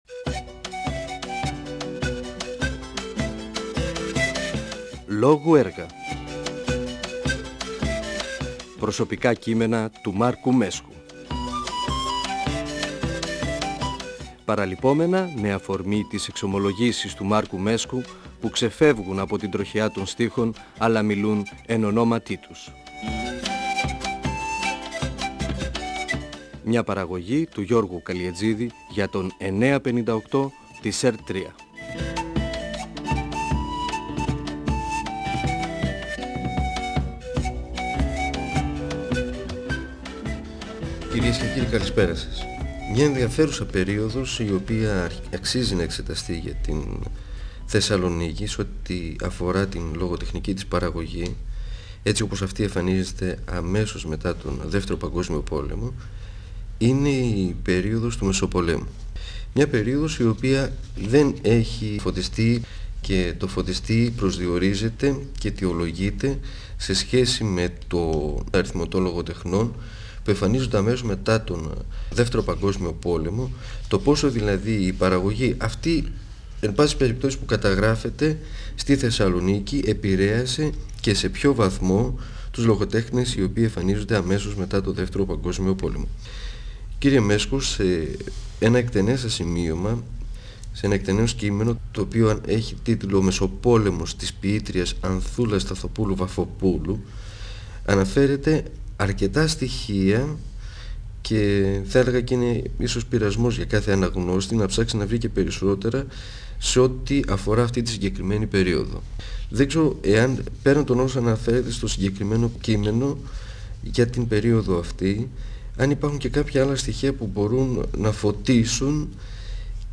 Ο ποιητής και δοκιμιογράφος Μάρκος Μέσκος (1935-2019) μιλά (εκπομπή 1η) για το ποιητικό έργο της Ανθούλας Σταθοπούλου (1908-1935), για τις οικογενειακές τραγωδίες που έζησε, για την γνωριμία της με τον Γρηγόριο Ξενόπουλο, για την έκδοση της ποιητικής της συλλογής και τις γνώμες και κριτικές λογοτεχνών τής εποχής, για τους ποιητές και τις ποιήτριες του μεσοπολέμου.